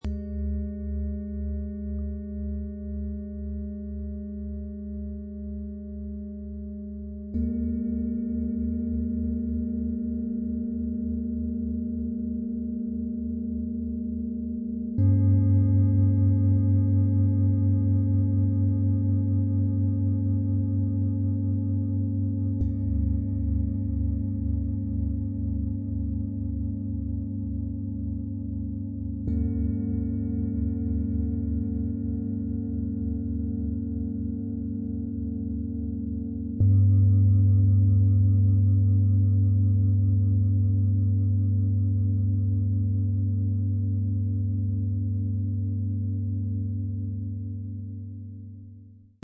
Erdung, Herz & Klarheit: für tiefes Vertrauen, heitere Kraft und einen freien Geist - XXL Profi-Set aus 3 Klangschalen, Ø 26,9 - 30,5 cm, 6,38 kg
Ihr ruhiger Klang legt ein sicheres Fundament.
Die mittlere Schale klingt hell, präsent und freundlich.
• Klang, der bleibt: langer, tragender Nachhall.
• Set: drei große, tief klingende Unikate
• Gefühl: warm, tragend, souverän, klärend
Im Sound-Player - Jetzt reinhören können Sie den Original-Ton genau dieser Schalen, des Sets anhören.